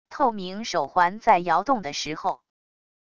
透明手环在摇动的时候wav音频